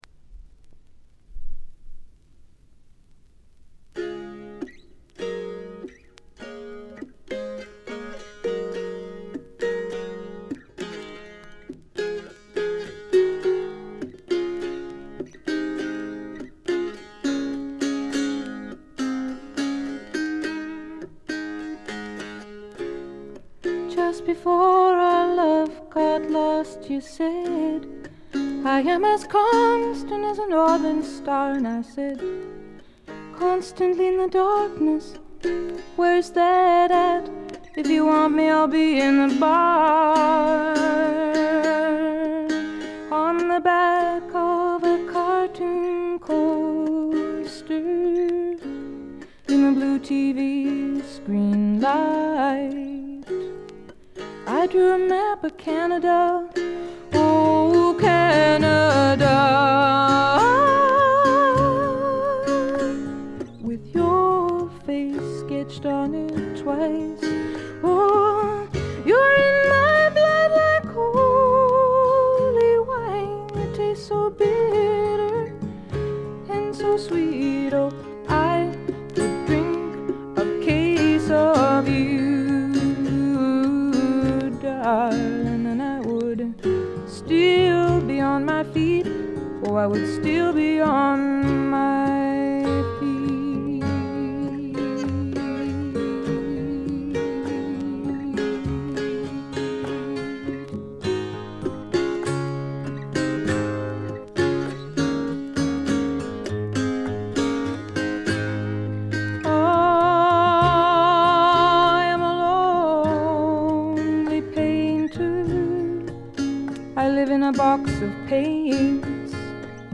全体にバックグラウンドノイズ。細かなチリプチ多めですが、鑑賞を妨げるほどのノイズはないと思います。
試聴曲は現品からの取り込み音源です。